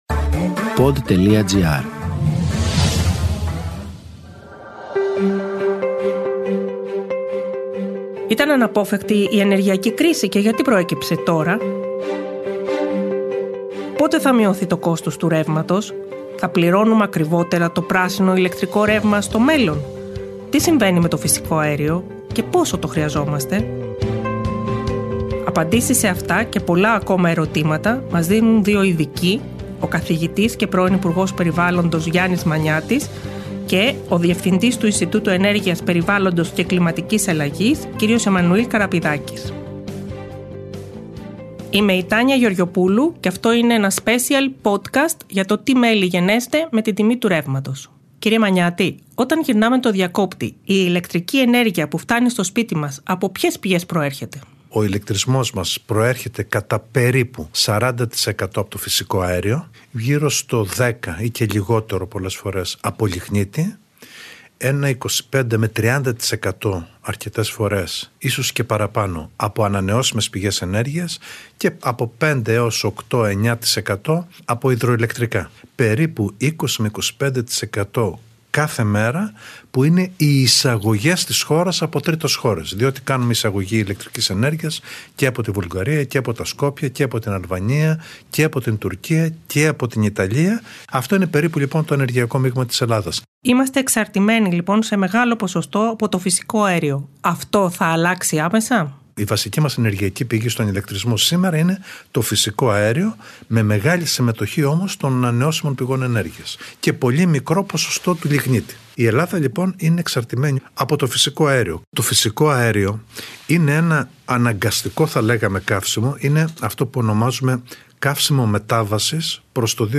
Η επικαιρότητα μέσα από ρεπορτάζ & συνεντεύξεις.